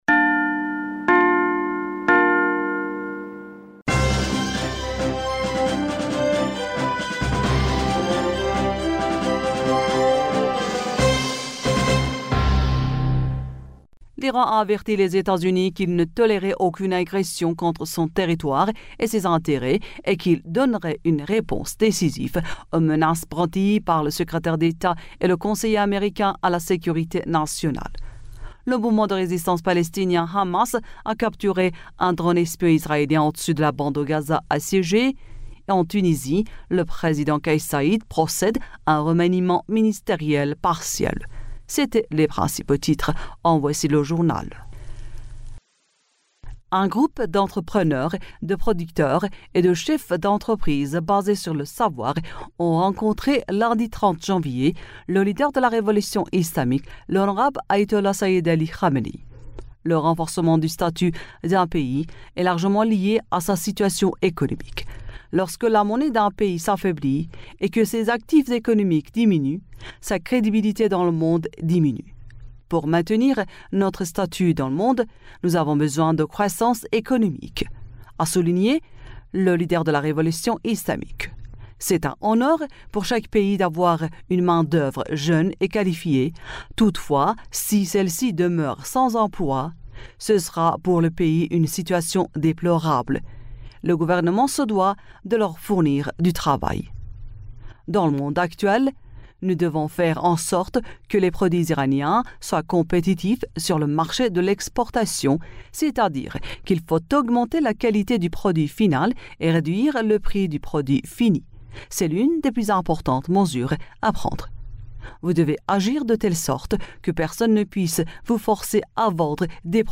Bulletin d'information du 31 Janvier